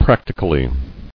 [prac·ti·cal·ly]